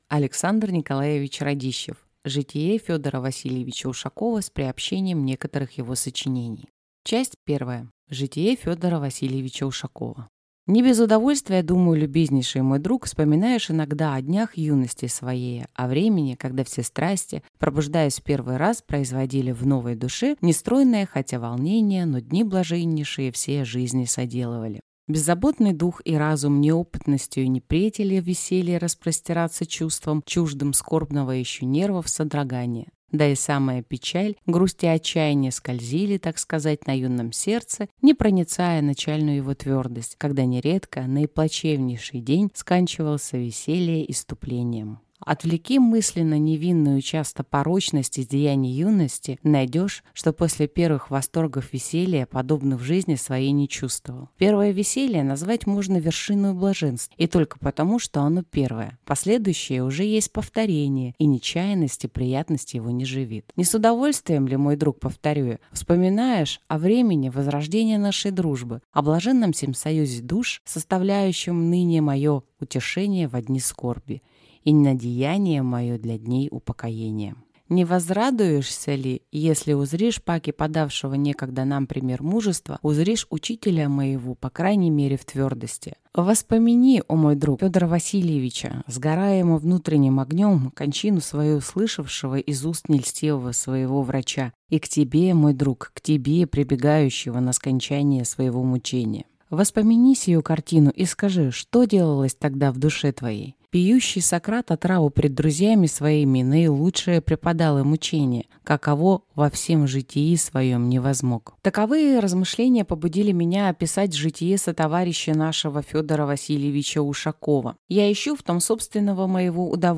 Аудиокнига Житие Федора Васильевича Ушакова с приобщением некоторых его сочинений | Библиотека аудиокниг